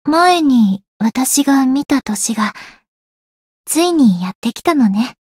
灵魂潮汐-星见亚砂-春节（摸头语音）.ogg